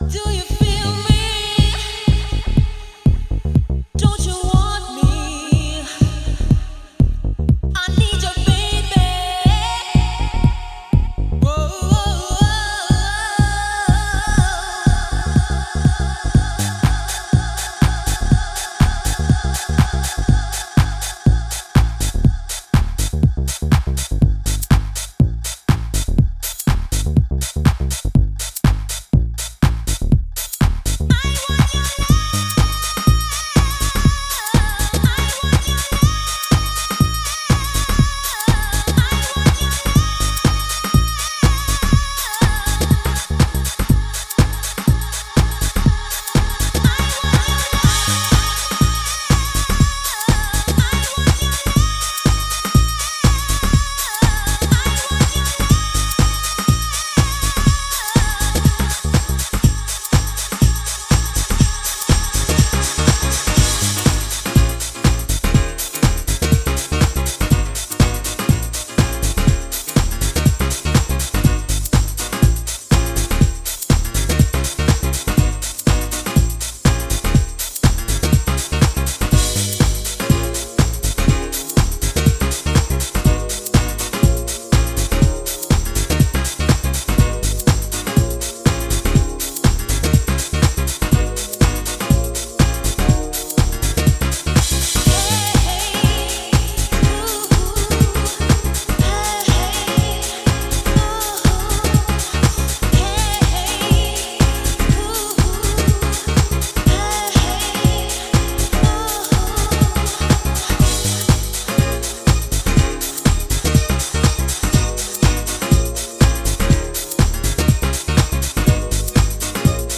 Super classic early 90's NY House production
House